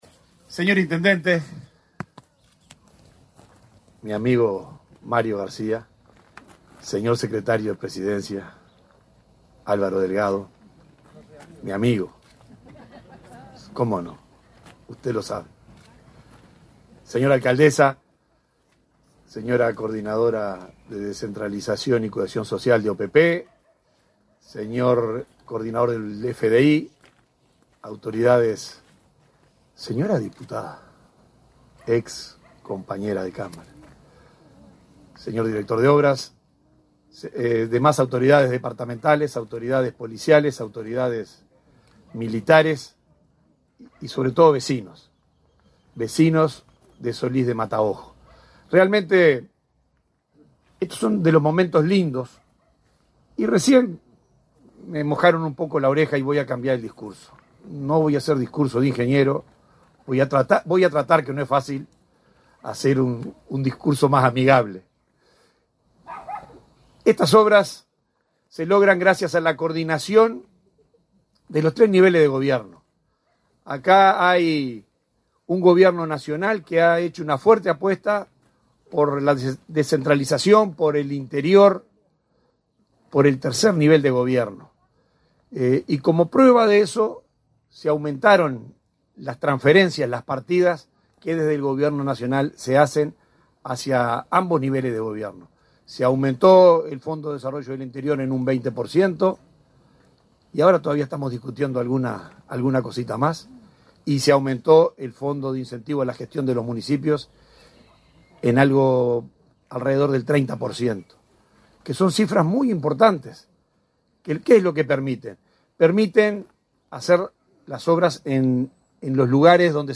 Palabras del subdirector de la OPP, Benjamín Irazabal
Palabras del subdirector de la OPP, Benjamín Irazabal 10/06/2023 Compartir Facebook X Copiar enlace WhatsApp LinkedIn Con la presencia del secretario de la Presidencia, Álvaro Delgado, este 10 de junio fueron inauguradas las obras de pavimentación realizadas por la Oficina de Planeamiento y Presupuesto (OPP) en el barrio El Chispero-Cánepa, de Solís de Mataojo, en Lavalleja. El subdirector de la dependencia, Benjamin Irazabal, realizó declaraciones.